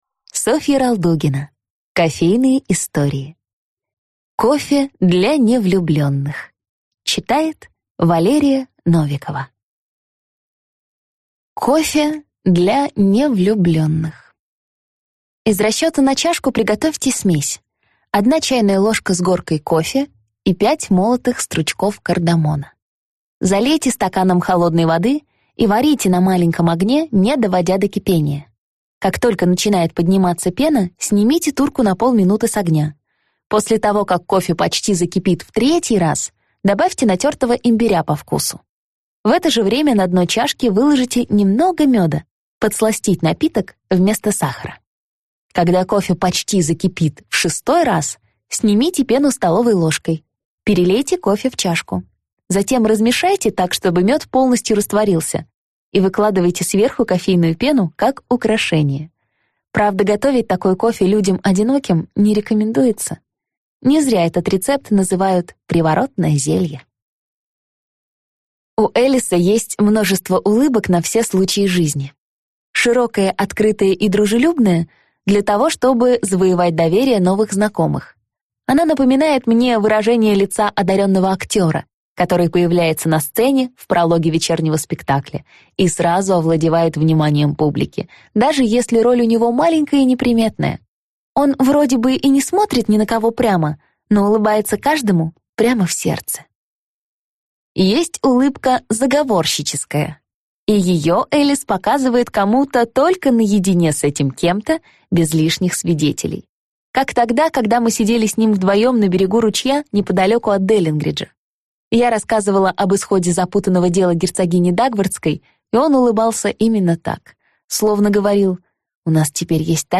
Аудиокнига Кофе для невлюбленных | Библиотека аудиокниг